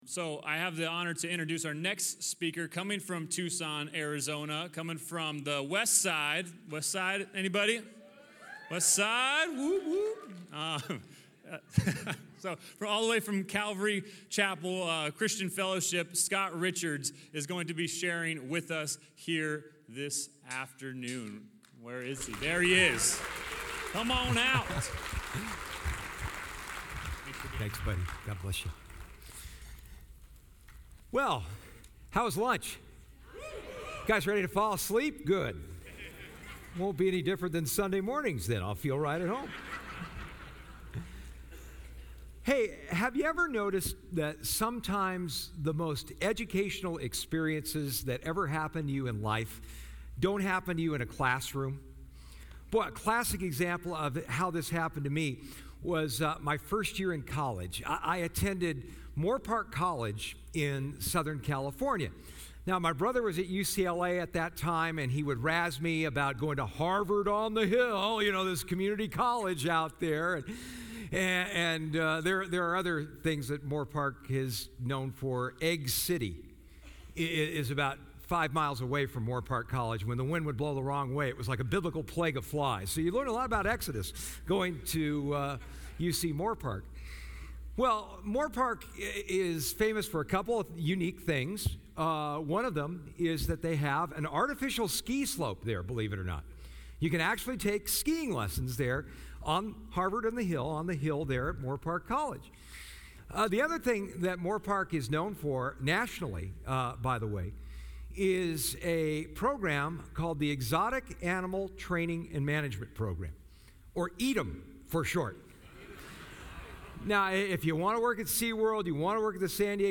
Southwest Pastors and Leaders Conference 2019